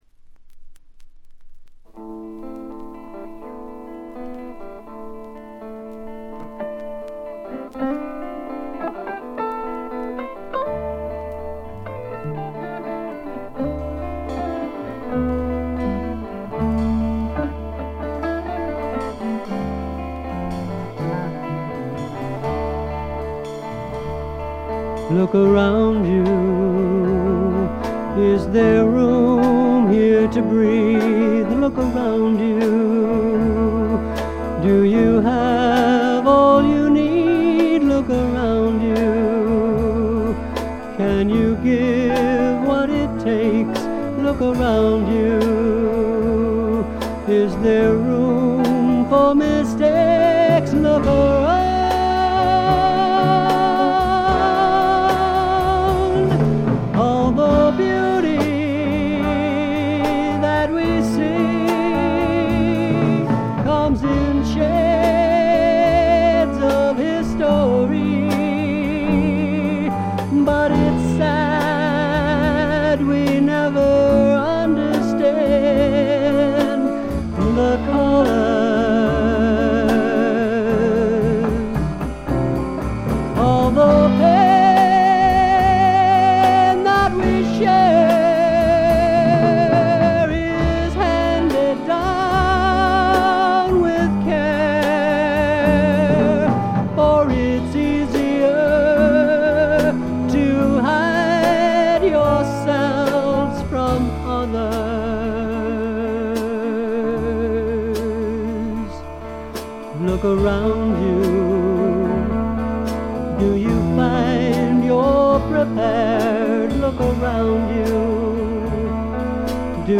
というわけで一度聴いたらクリアトーンのギターの音色が頭から離れなくなります。
特異な世界を見せつけるアシッド・フォークの傑作です。
試聴曲は現品からの取り込み音源です。
Guitar, Vocals, Producer, Written-By, Arranged By ?